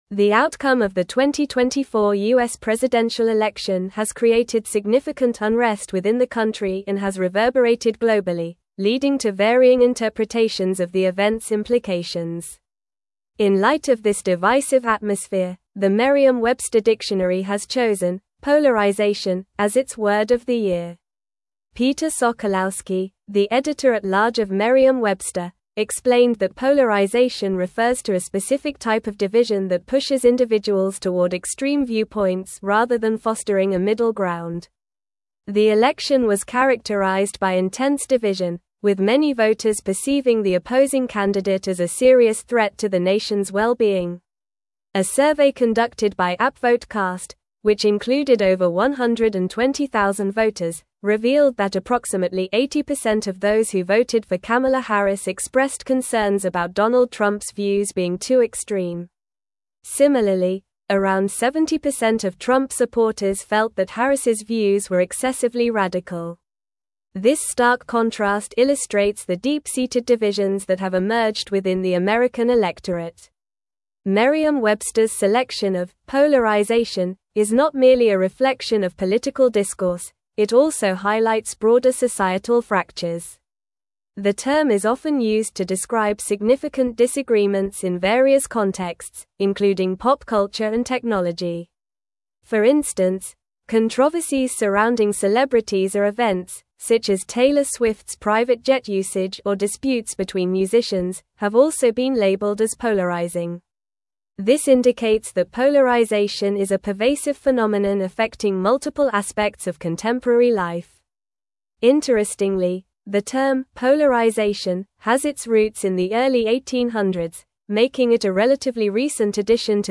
Normal
English-Newsroom-Advanced-NORMAL-Reading-Polarization-Named-Merriam-Websters-Word-of-the-Year.mp3